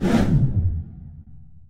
whooshFast.mp3